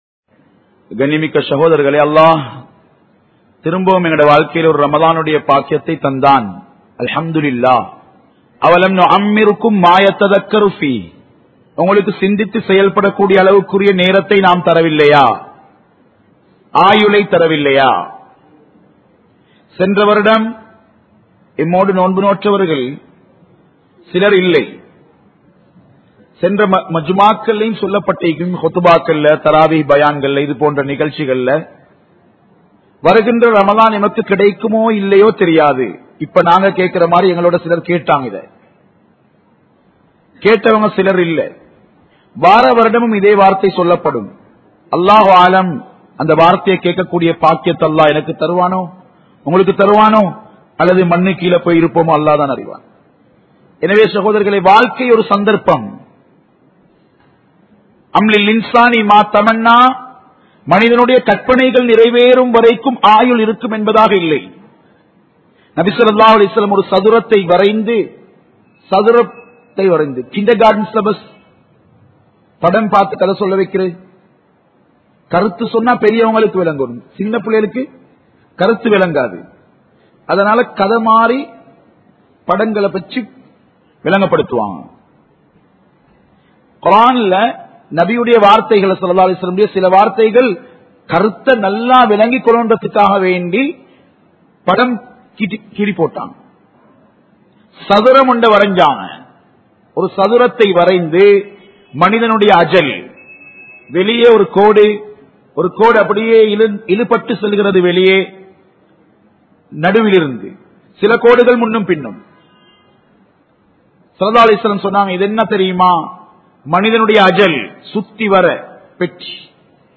Seiththaankal Vaalum Sila Manitharhalin Veeduhal (சைத்தான்கள் வாழும் சில மனிதர்களின் வீடுகள்) | Audio Bayans | All Ceylon Muslim Youth Community | Addalaichenai
Colombo 11, Samman Kottu Jumua Masjith (Red Masjith)